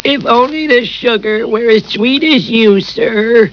Voiced by Dan Castellaneta